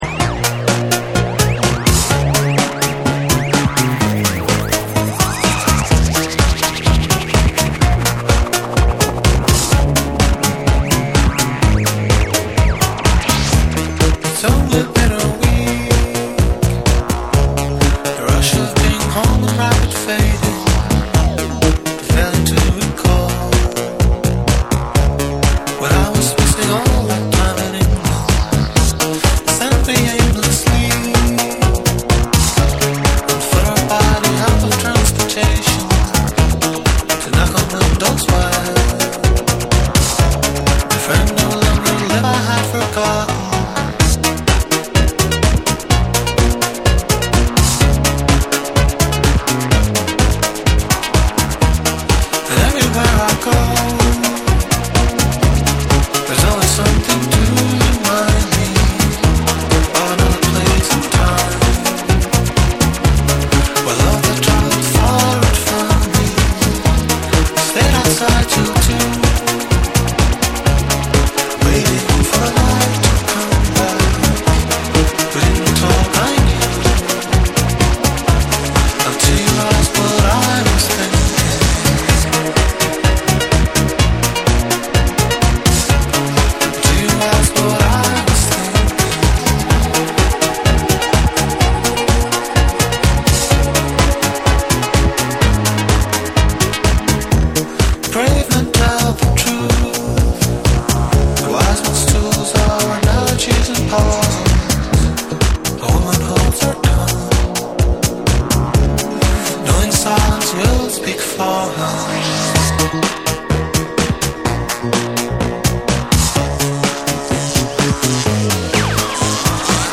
キャッチーでエレクトリックなハウス・ナンバーを収録！
TECHNO & HOUSE